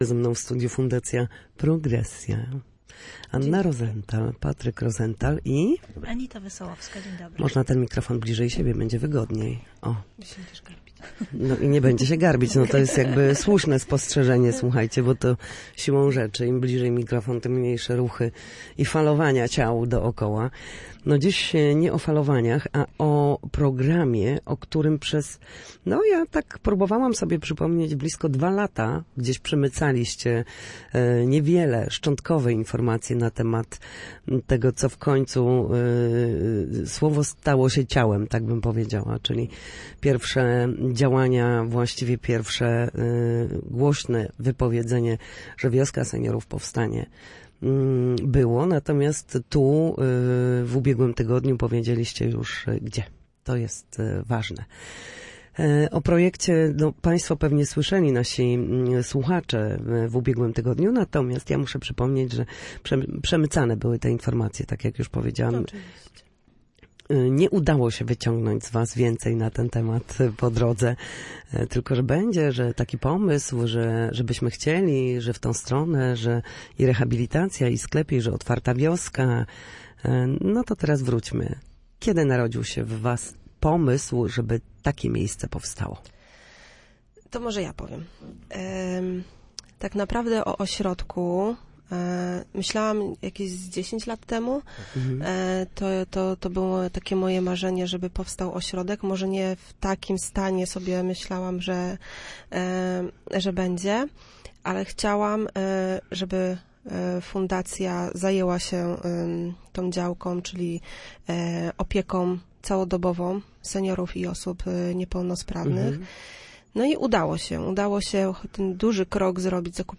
O projekcie opowiadali goście Studia Słupsk